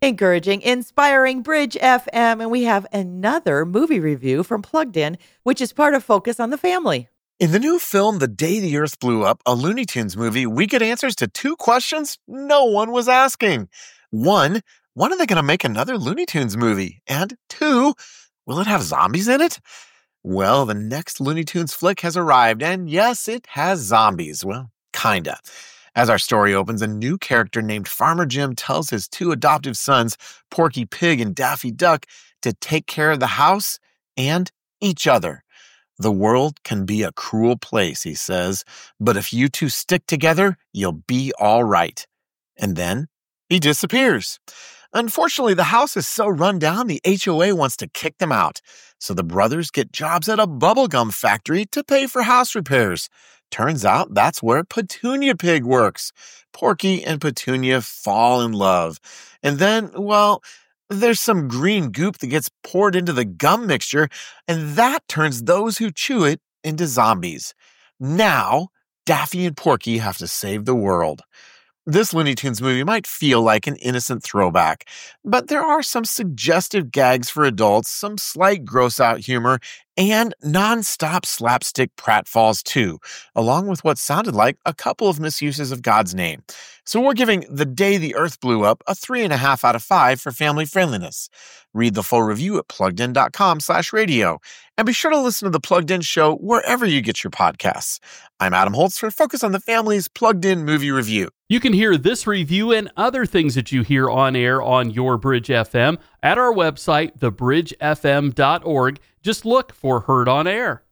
Movie Review – The Day the Earth Blew Up: A Looney Tunes Movie
Here’s the review of The Day the Earth Blew Up: A Looney Tune Movie. You heard it on the morning show from Plugged In, which is a part of Focus on the Family.